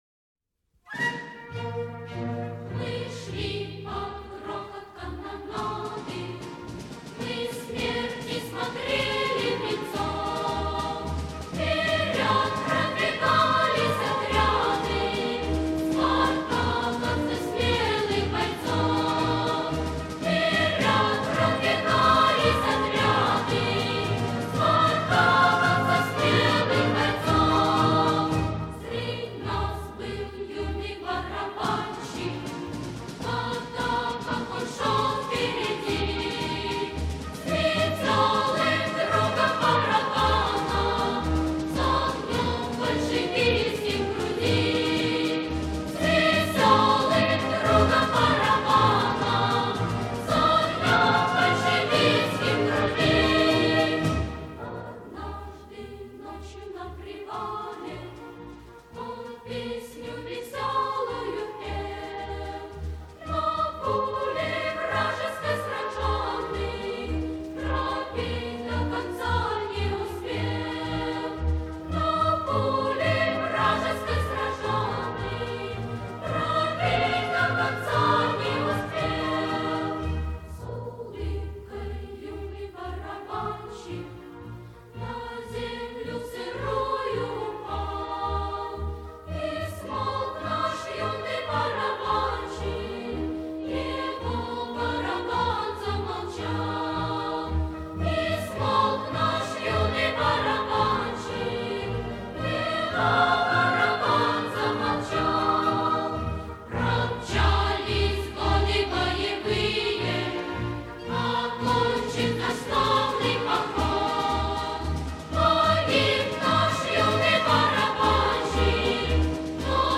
Немецкая революционная песня